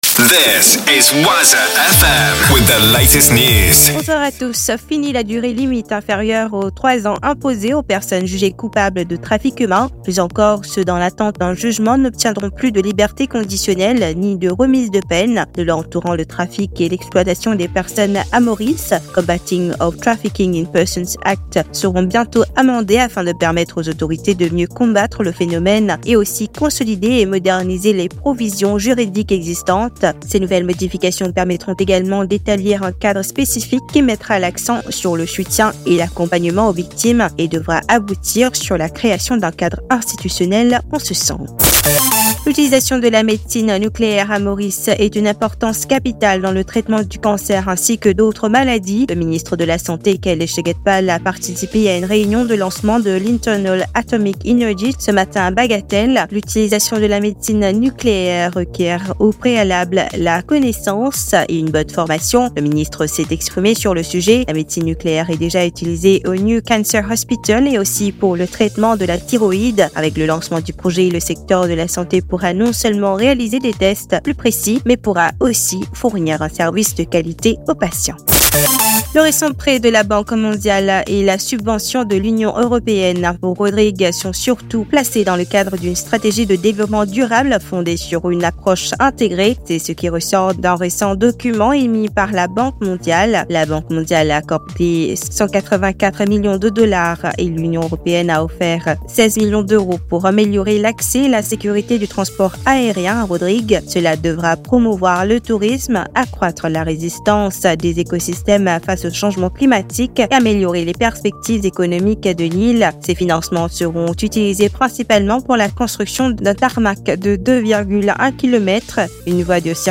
NEWS 19H - 13.11.23